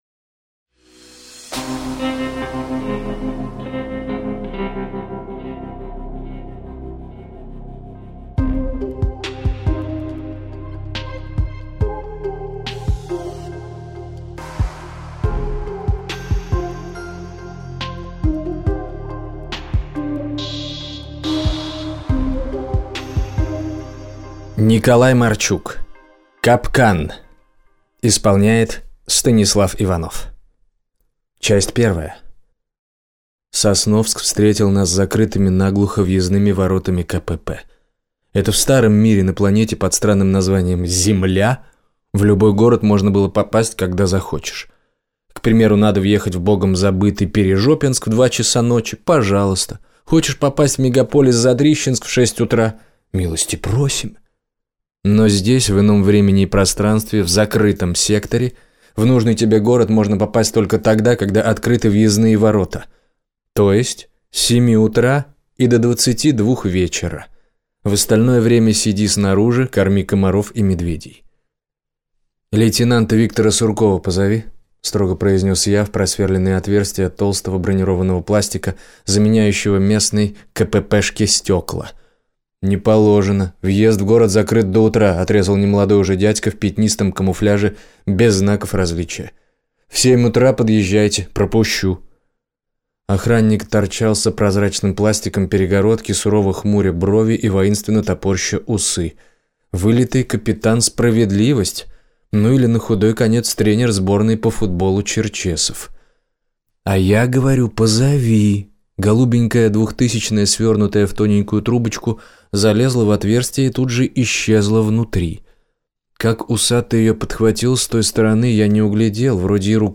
Аудиокнига Закрытый сектор. Капкан | Библиотека аудиокниг